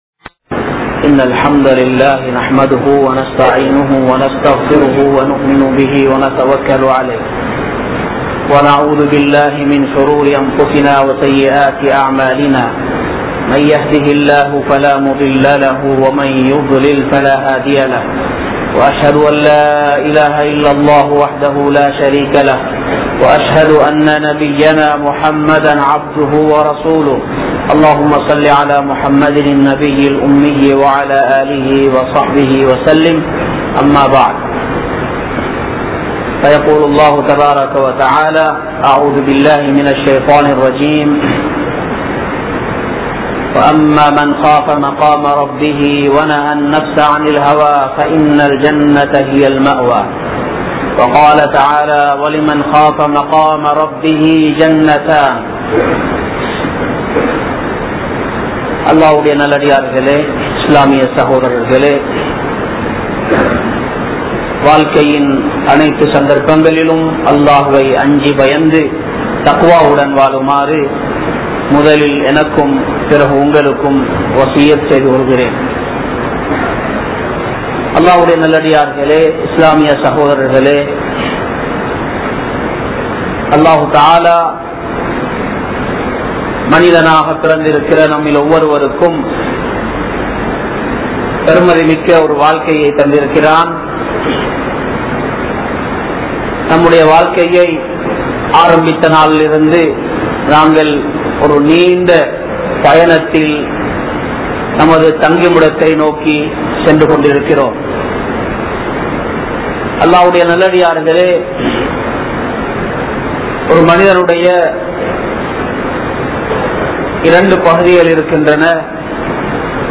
Keavalamaana Roohuhal(கேவலமான றூஹூஹல்) | Audio Bayans | All Ceylon Muslim Youth Community | Addalaichenai